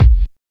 Wu-RZA-Kick 3.wav